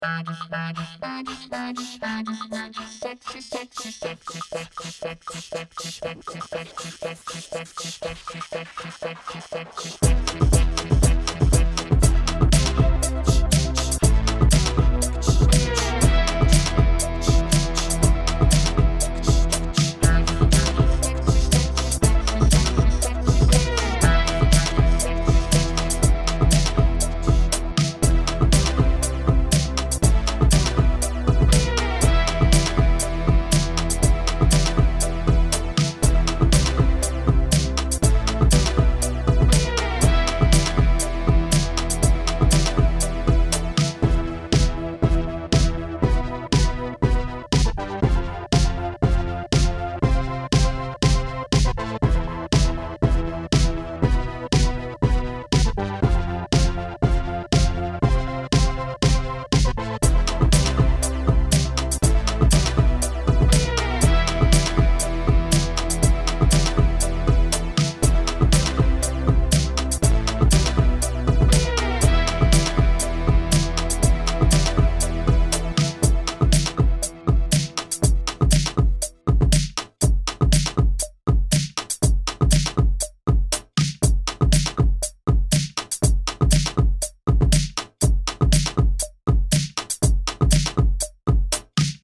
dance/electronic